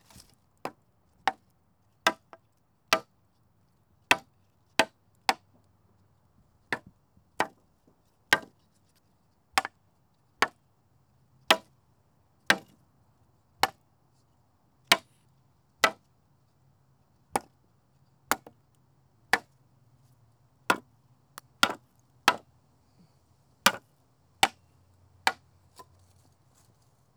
Вложения hitting wood.wav hitting wood.wav 5 MB · Просмотры: 142